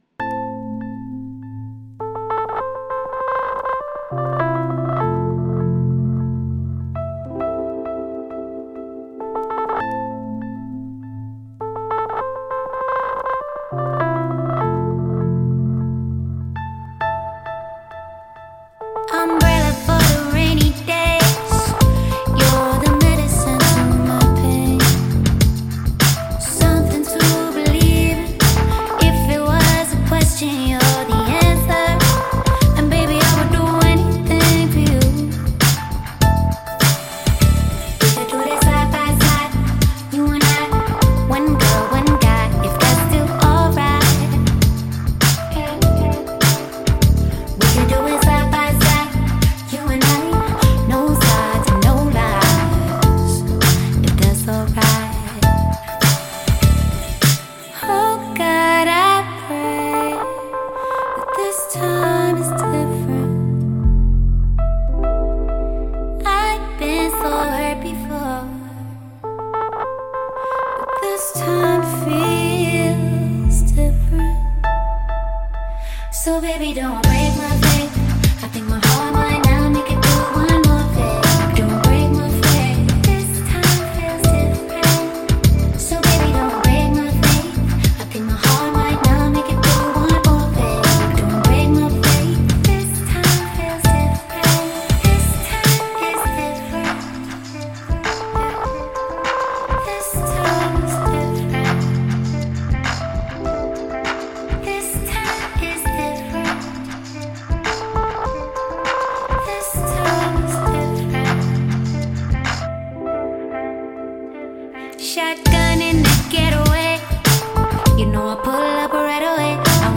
# R&B # Soul